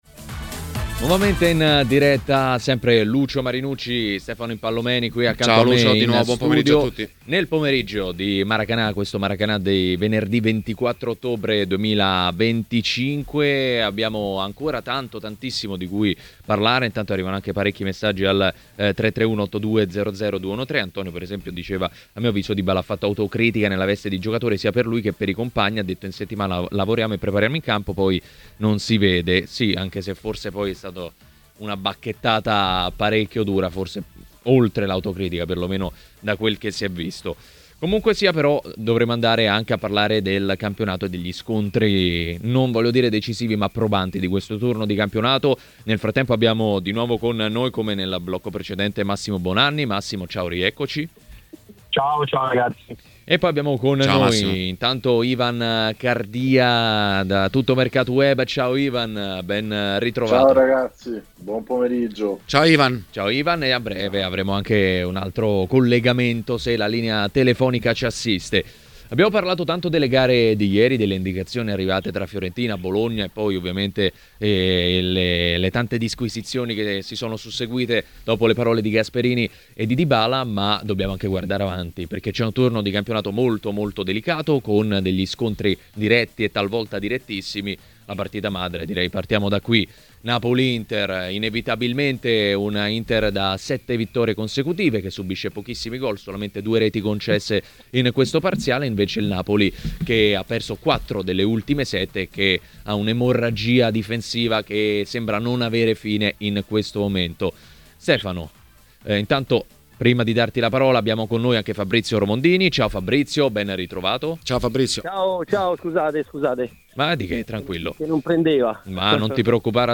Le Interviste
intervenuto sulle frequenze di TMW Radio nel corso di Maracanà